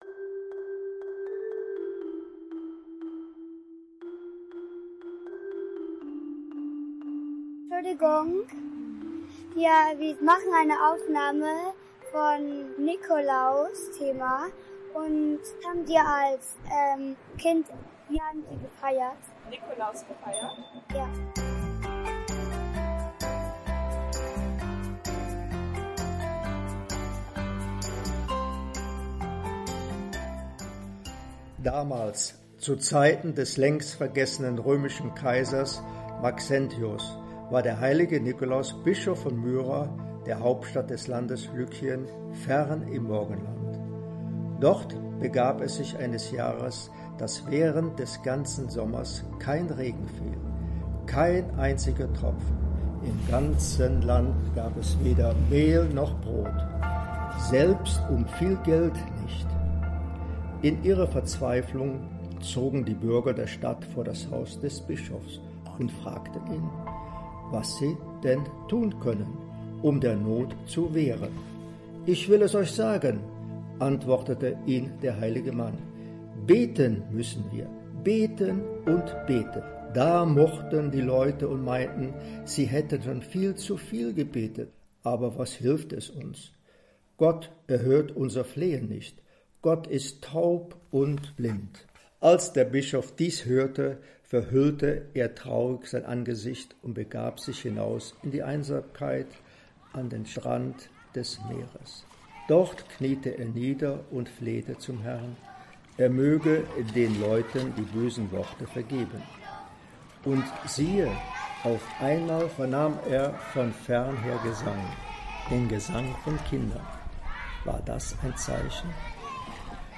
Hier ein Hörspiel für und von allen Generationen.